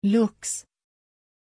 Aussprache von Lux
pronunciation-lux-sv.mp3